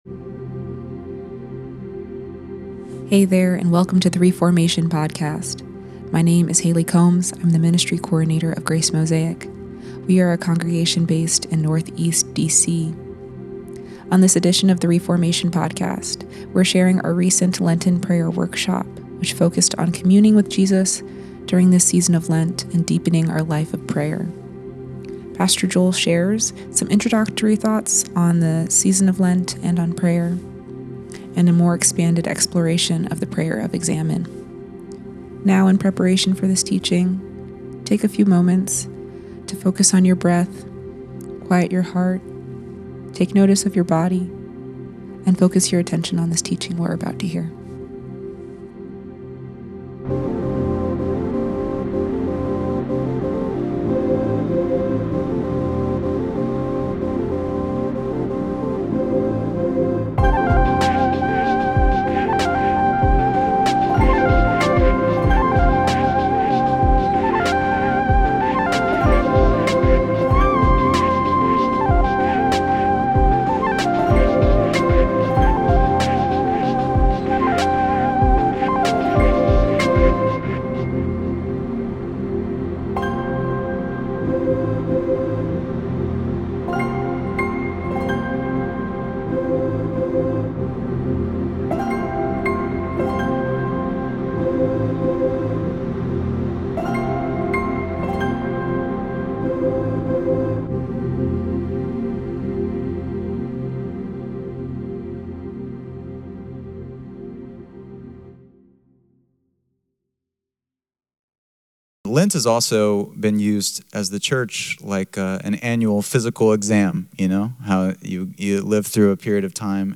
Lenten Prayer Workshop